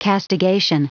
Prononciation du mot castigation en anglais (fichier audio)
Prononciation du mot : castigation